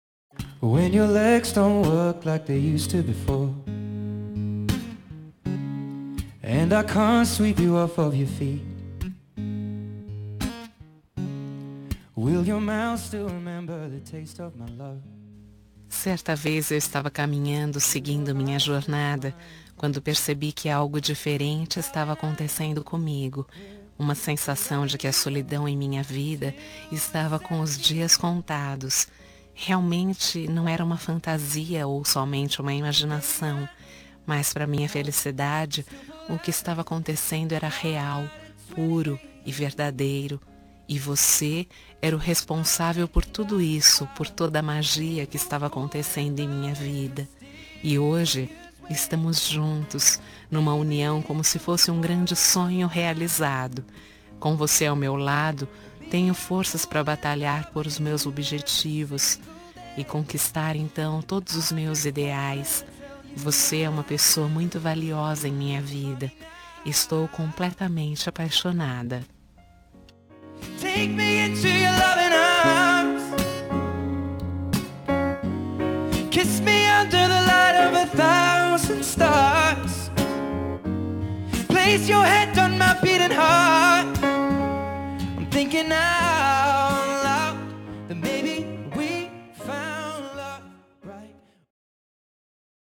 Telemensagem Início de Namoro – Voz Feminina – Cód: 741